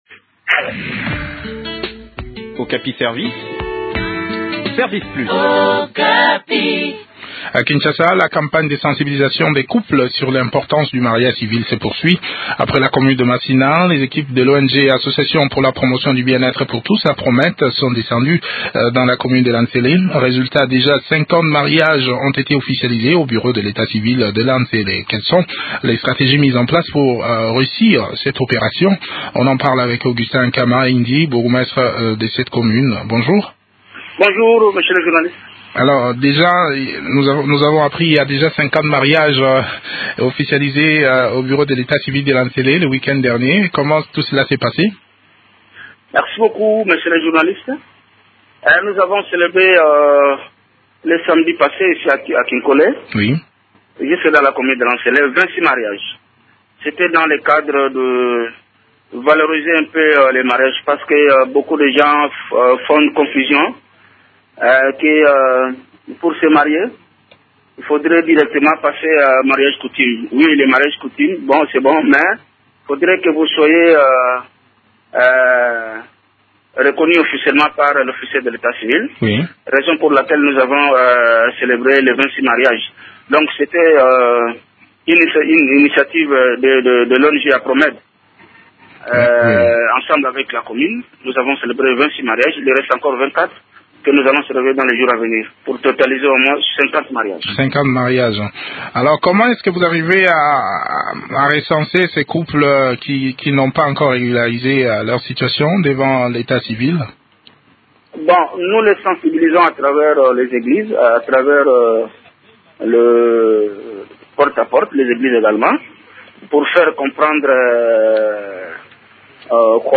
s’entretien sur le déroulement de cette campagne avec Augustin Nkama Indi, bourgmestre de la maison communale de N’sele.